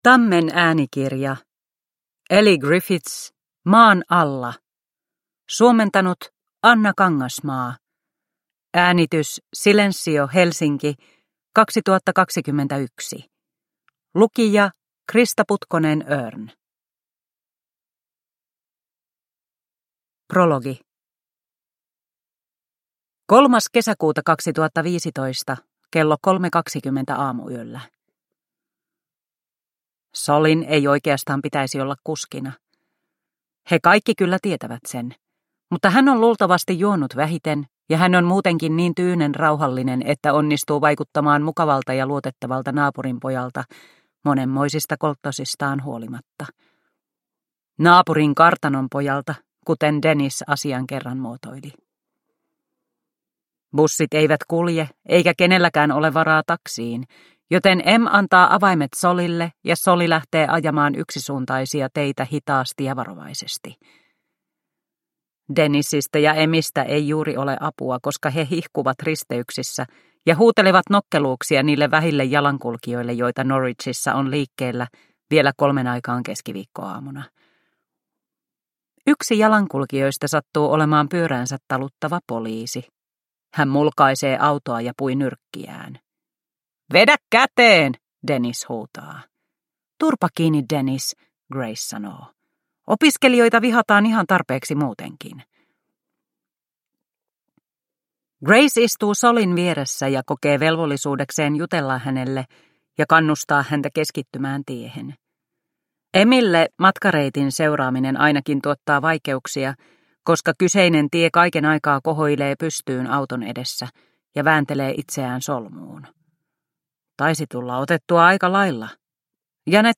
Maan alla – Ljudbok – Laddas ner